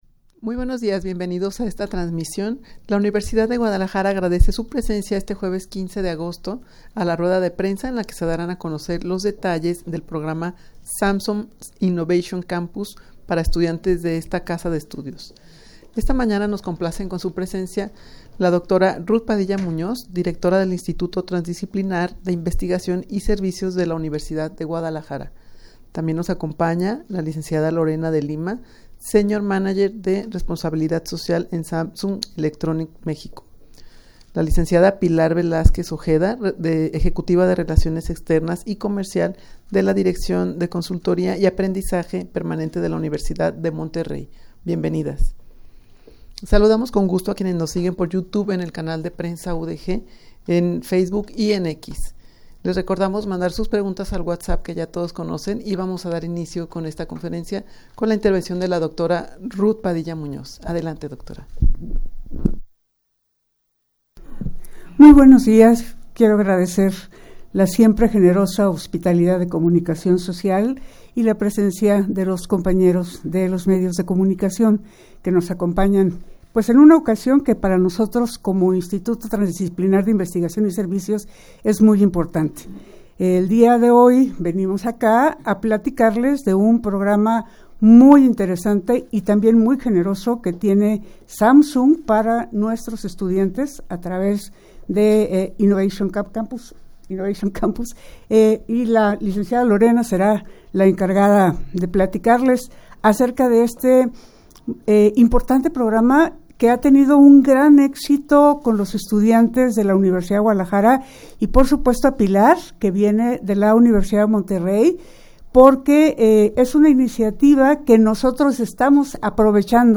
rueda-de-prensa-en-la-que-se-daran-a-conocer-detalles-del-programa-samsung-innovation-campus_0.mp3